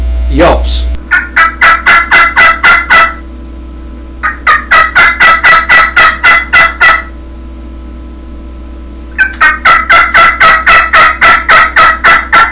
• Makes medium-toned and raspy yelps, cackles, clucks, and cutts at all volume levels.
qbgroldbosshenyelps13.wav